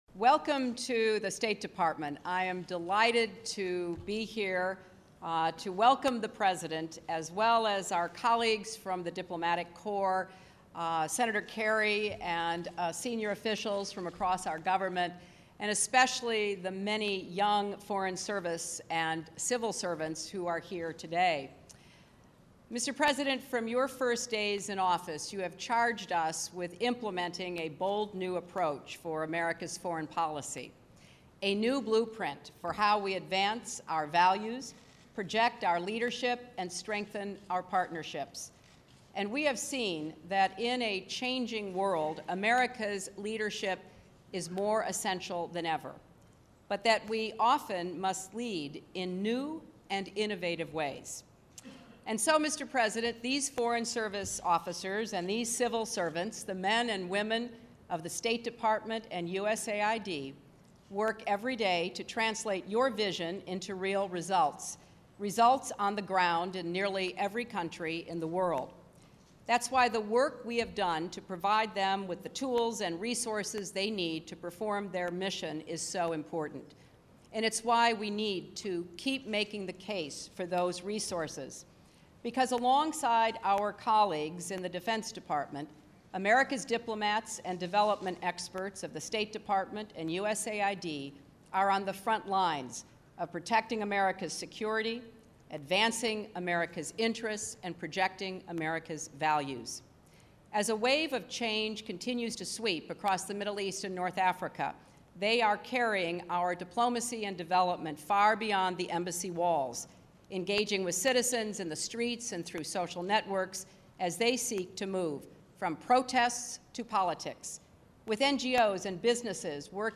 He calls again for a two state solution and the creation of a Palestinian state with borders reflecting the situation prior to the 1967 Six Day War. Secretary of State Hillary Rodham Clinton opens the session held at the State Department, Washington, DC.
Recorded at the State Dept., Washington, D.C., May 19, 2011.